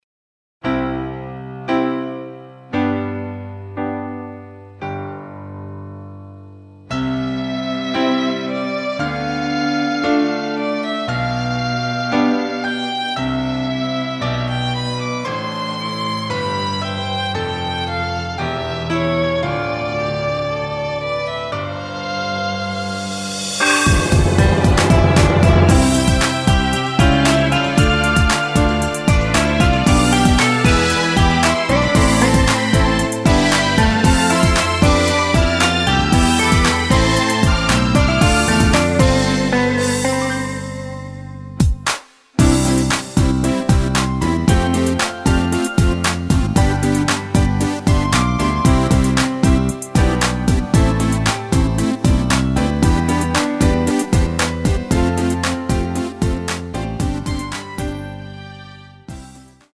전주 없이 시작 하는 곡이라 전주 2마디 만들어 놓았습니다.
앞부분30초, 뒷부분30초씩 편집해서 올려 드리고 있습니다.